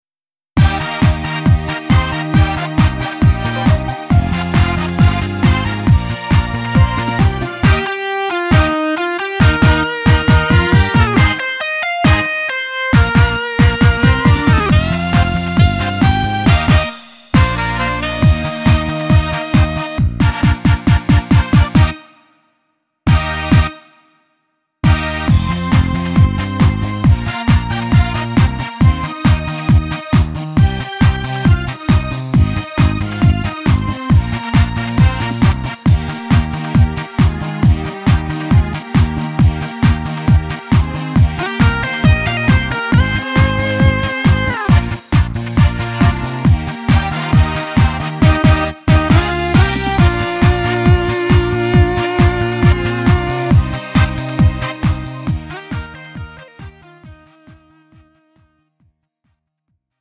음정 -1키 3:33
장르 가요 구분 Lite MR
Lite MR은 저렴한 가격에 간단한 연습이나 취미용으로 활용할 수 있는 가벼운 반주입니다.